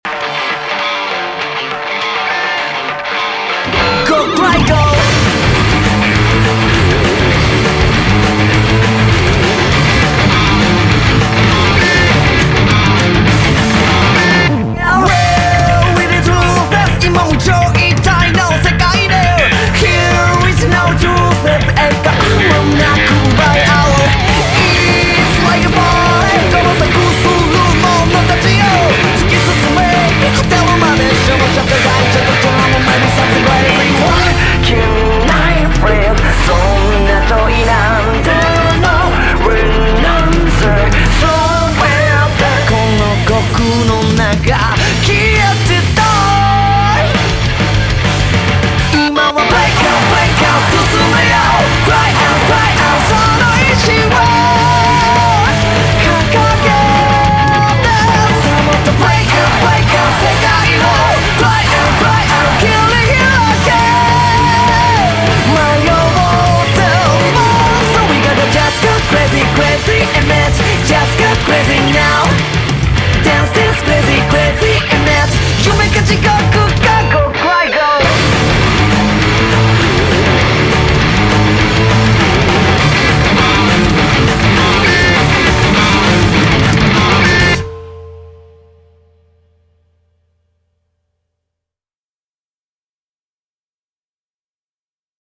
BPM101-202
MP3 QualityMusic Cut